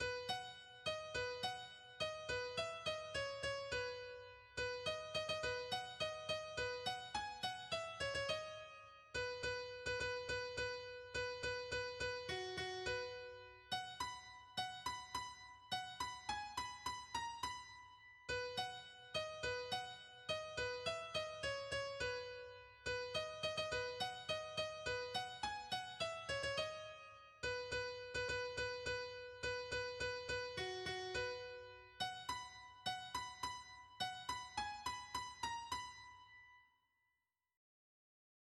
“To Portsmouth,” twice through, on a synthesized grand piano, in B.